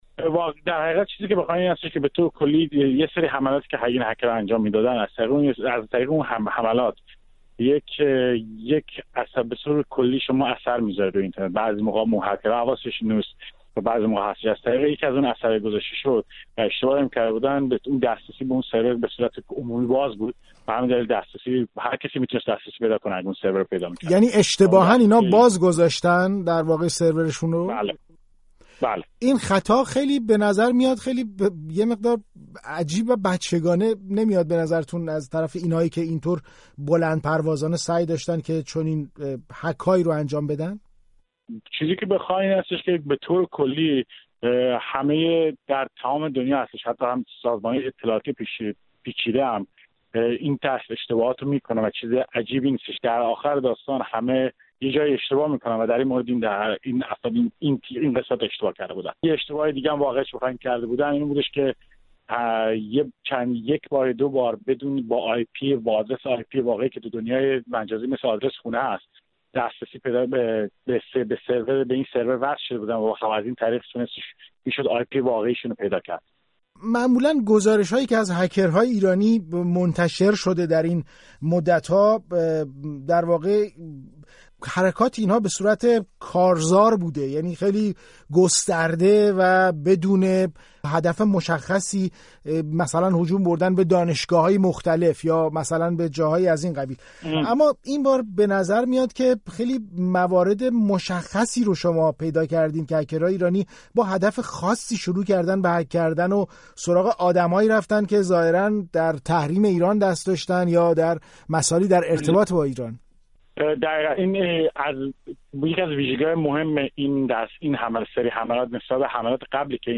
گفت‌وگو با یک کارشناس امنیت سایبری درباره حملات هکرهای «بچه‌گربه ملوس»